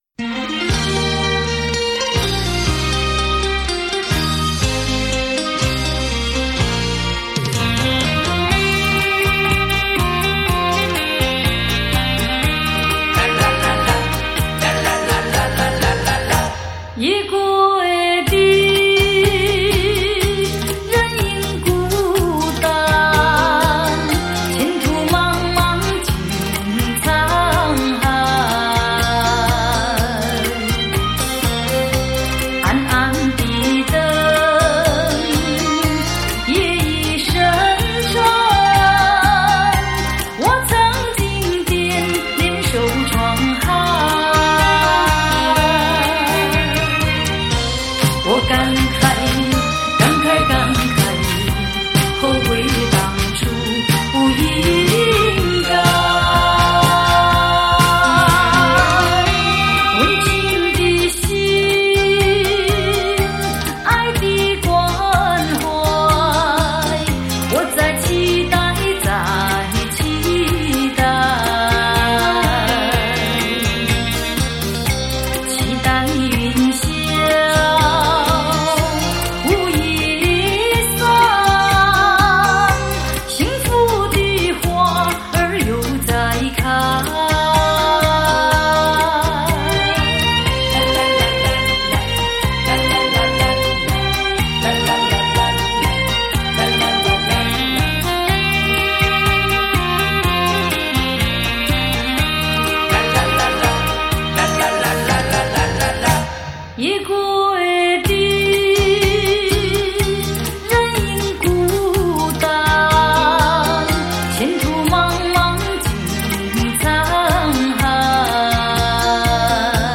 福建译曲